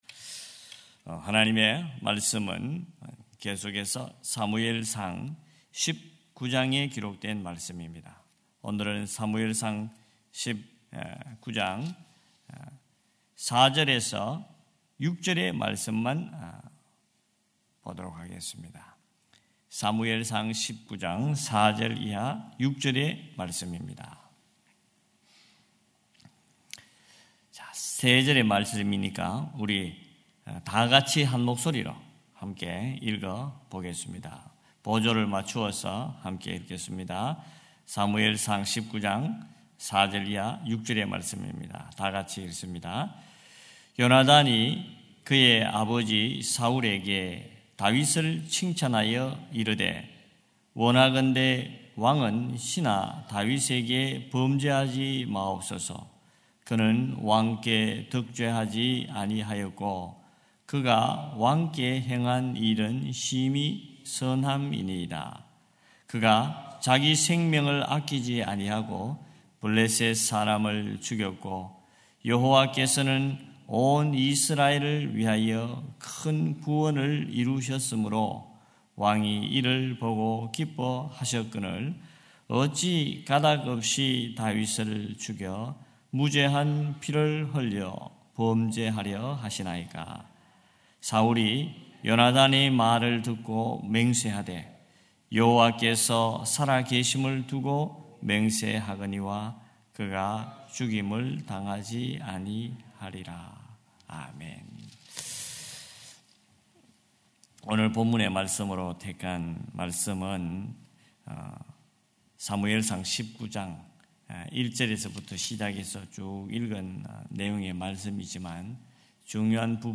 4.26.2025 새벽예배 사무엘상 19장 4절-6절